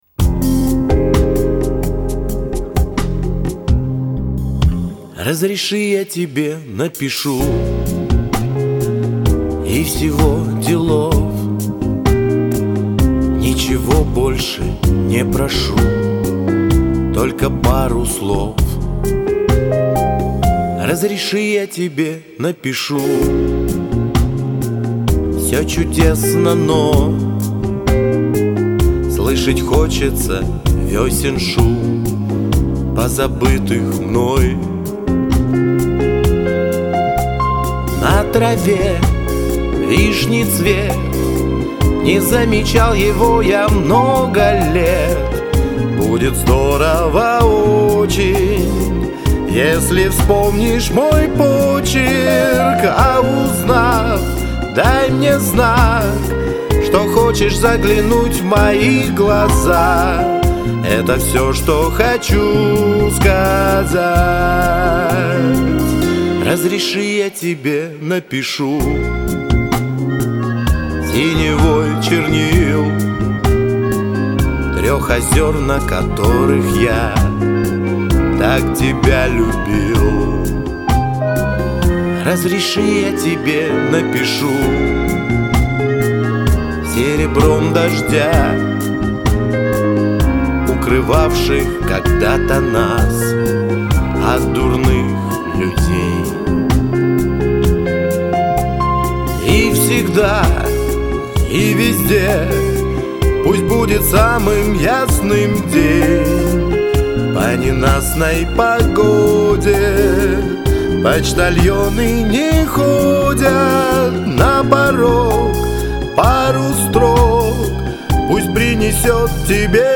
Комментарий инициатора: Условие одно: спеть как гимн!